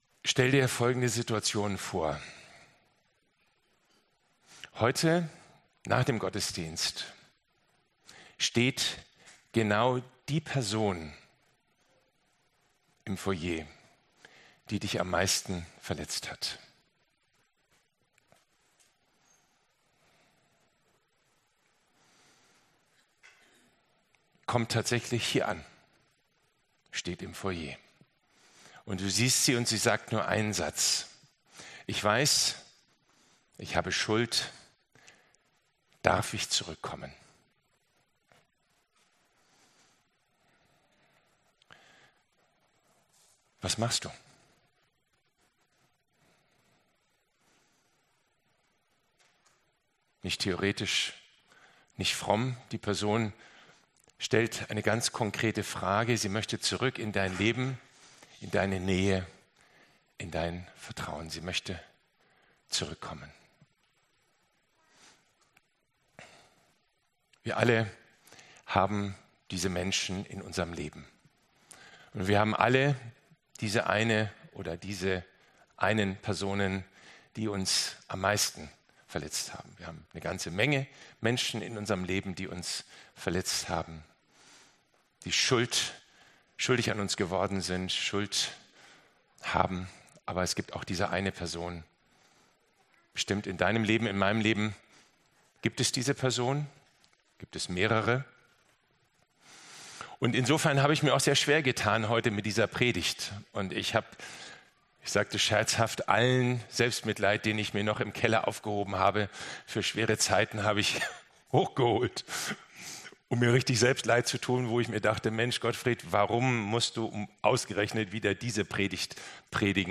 Predigtzusammenfassung